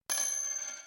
loot_gold.mp3